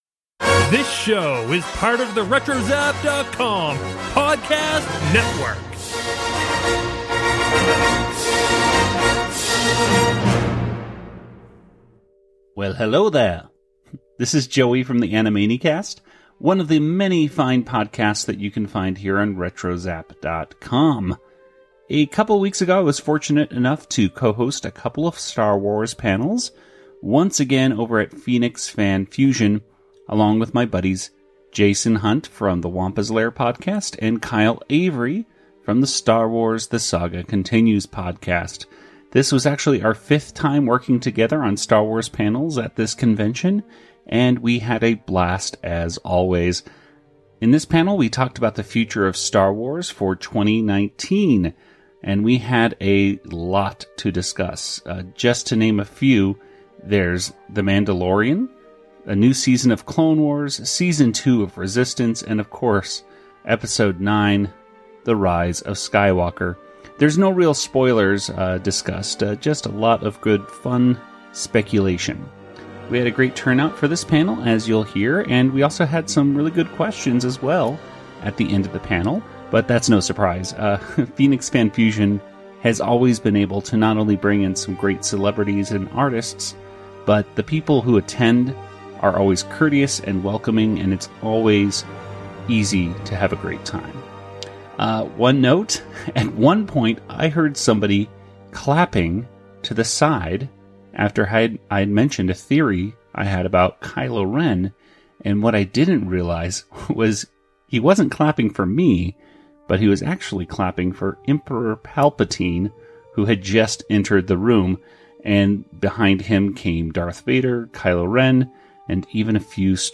The Future of Star Wars 2019 from Phoenix Fan Fusion